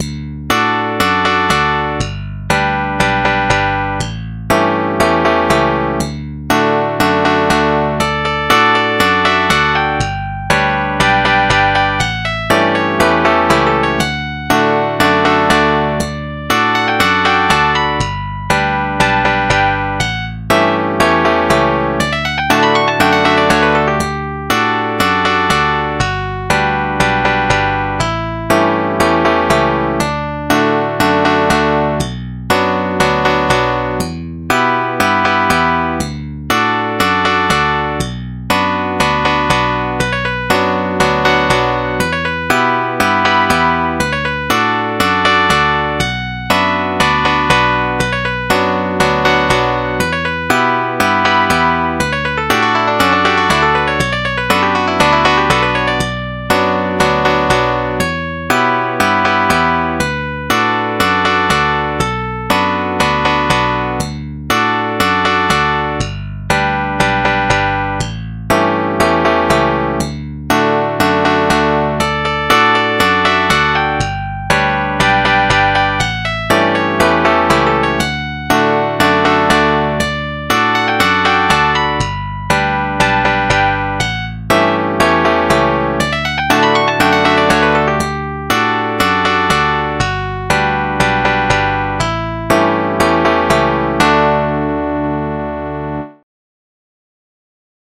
1 lol crappy midi wrote by me